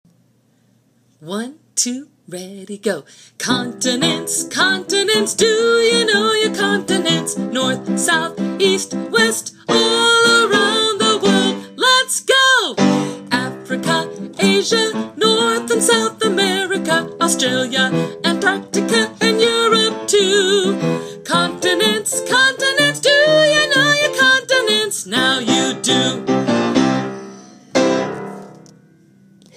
Continents Chant Put to Song